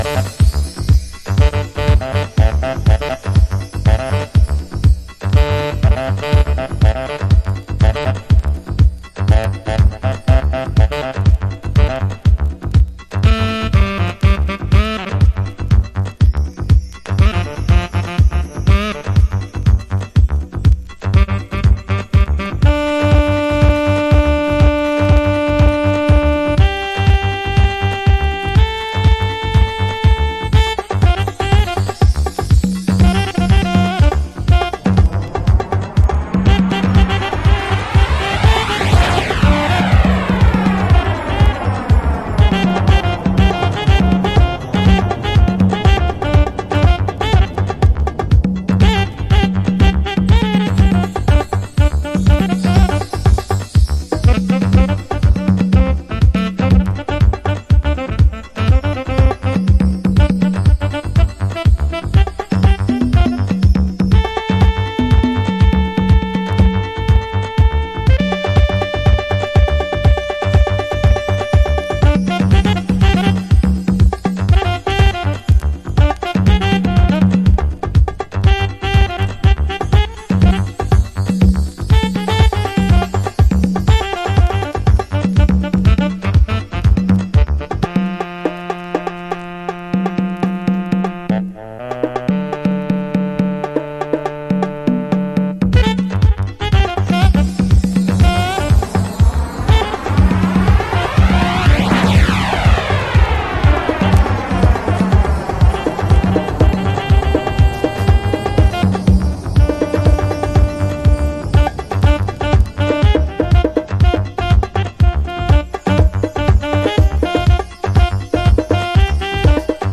Early House / 90's Techno
オリジナルは92年のリリース、90's Deep House Classic。